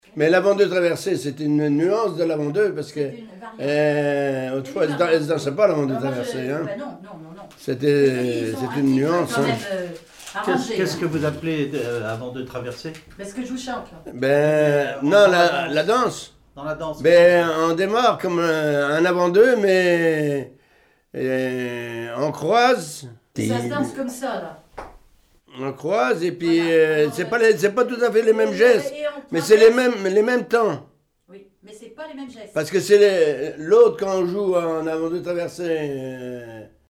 Répertoire de marches de noces et d'avant-deux
Catégorie Témoignage